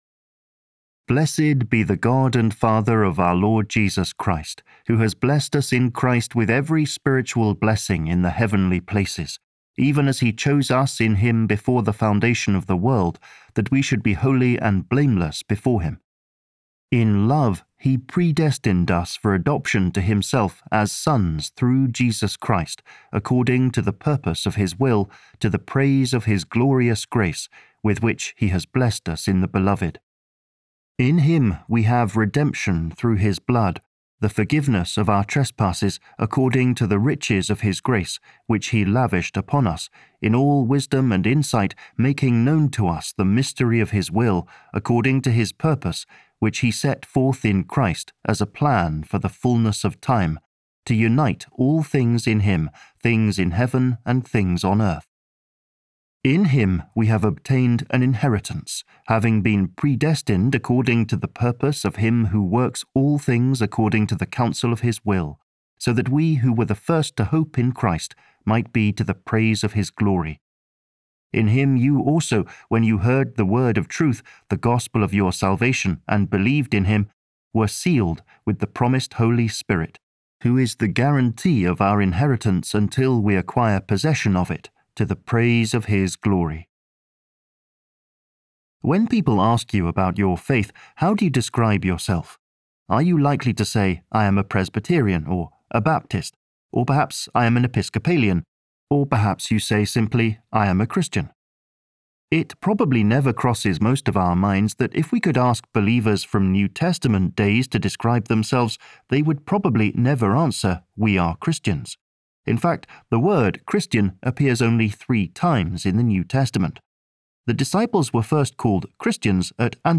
Audiobook Download